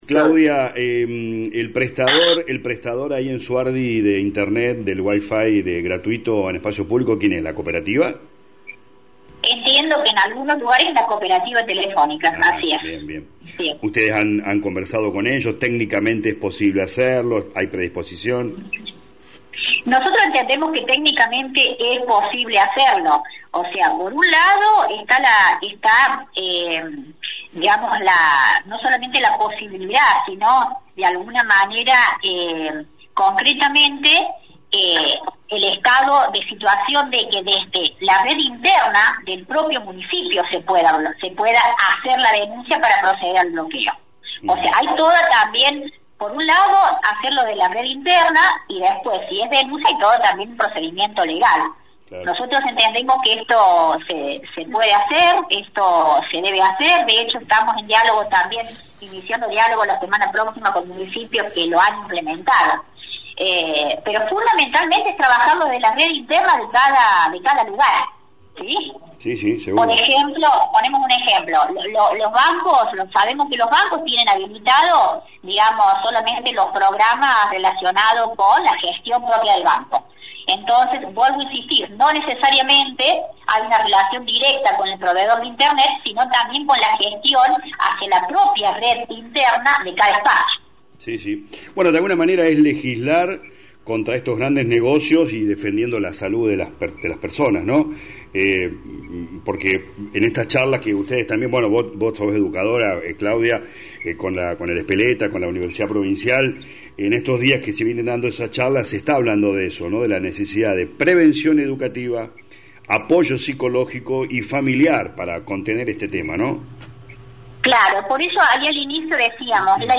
LA RADIO 102.9 habló con los concejales Lic. Claudia Echazarreta y el Dr. Roberto Pelussi quienes explican los alcances de la ordenanza…